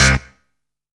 DEEP HIT.wav